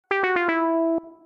Download Musical Fail sound effect for free.
Musical Fail